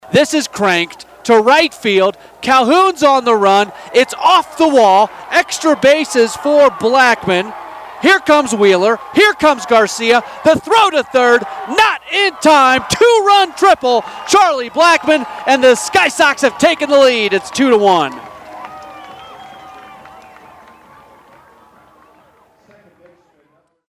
Here are some audio highlights from the voices of the Sky Sox